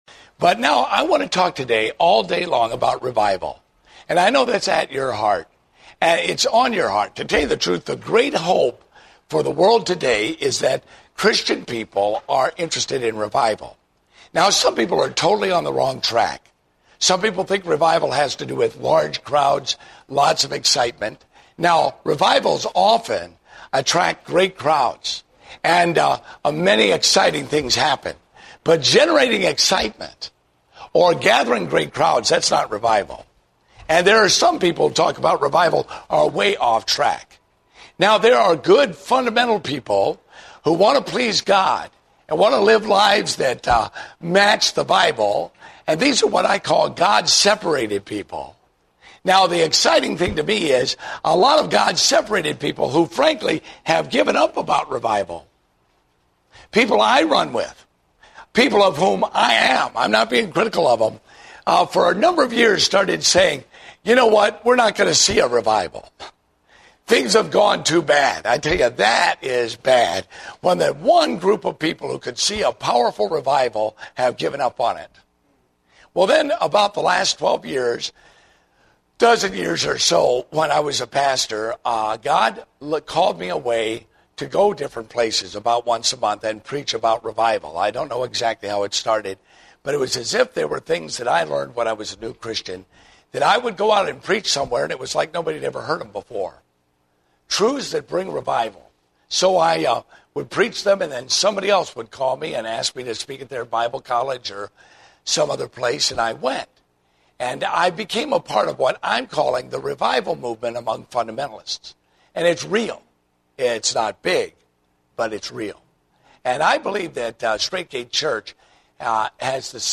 Date: August 29, 2010 (Adult Sunday School)